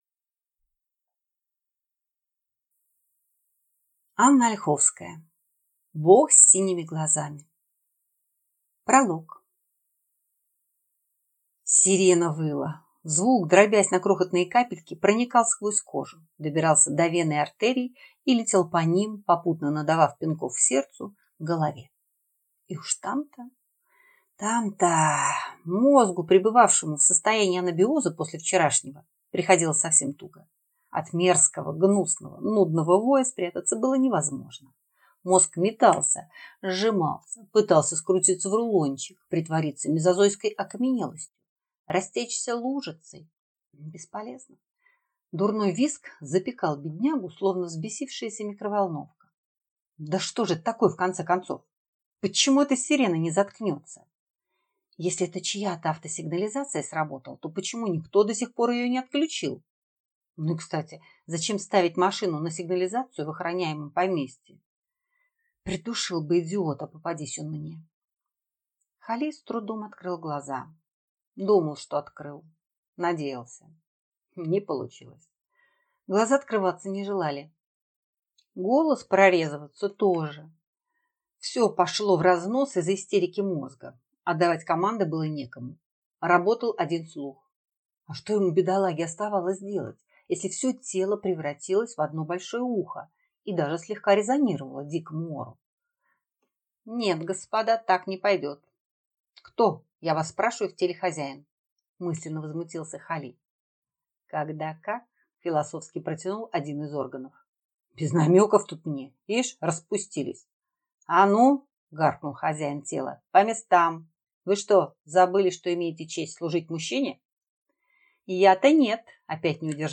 Аудиокнига Бог с синими глазами | Библиотека аудиокниг
Прослушать и бесплатно скачать фрагмент аудиокниги